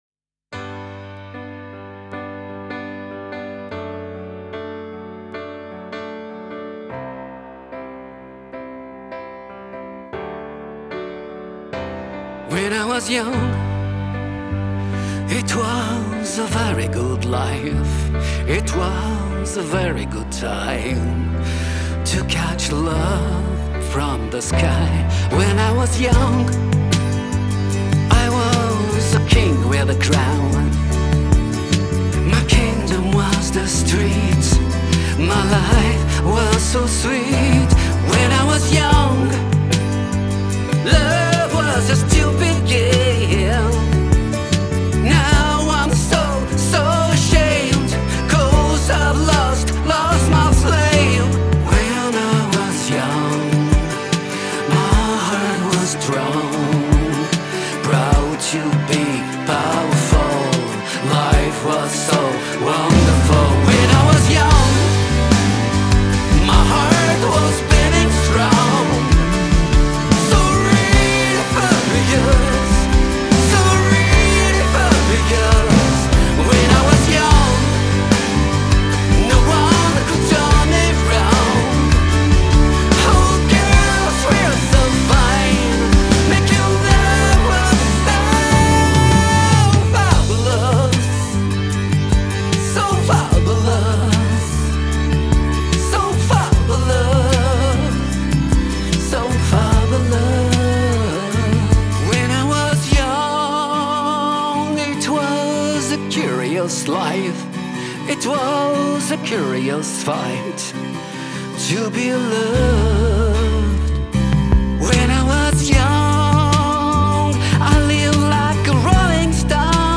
12. Pop Rock version